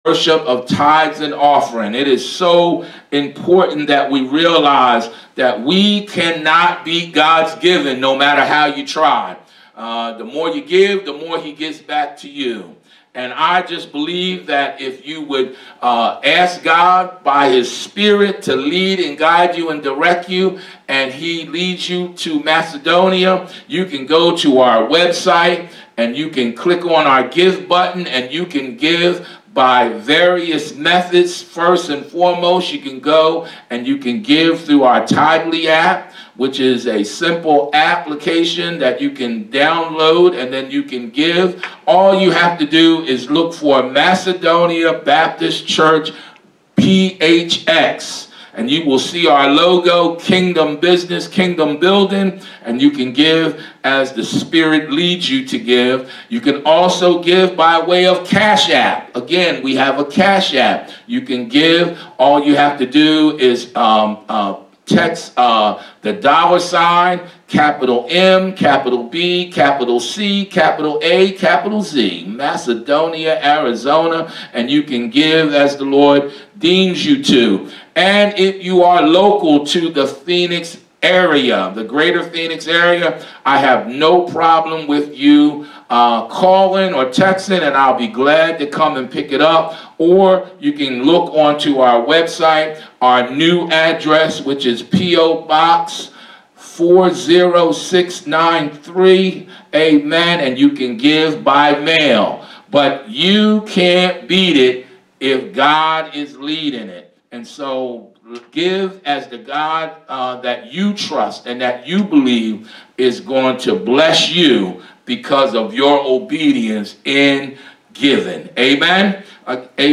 Sermons | Macedonia Baptist Church